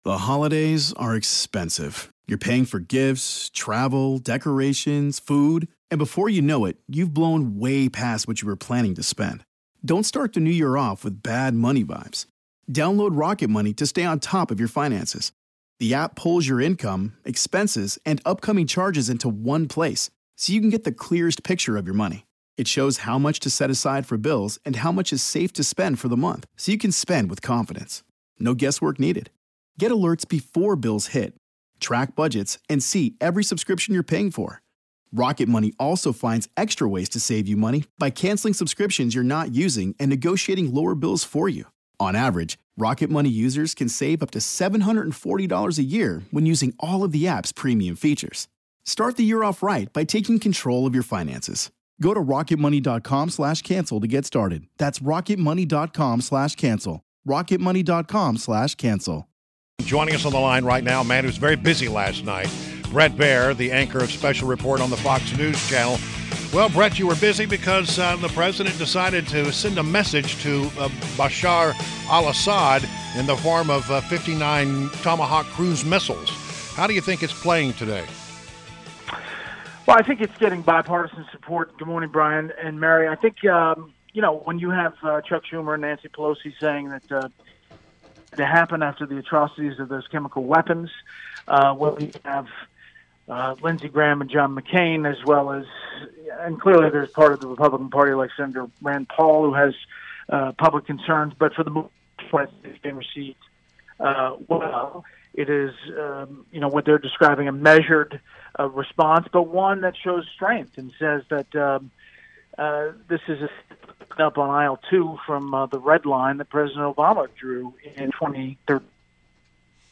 INTERVIEW — BRET BAIER – ANCHOR of SPECIAL REPORT on FOX NEWS CHANNEL – discussed the airstrikes on Syria.